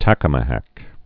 (tăkə-mə-hăk)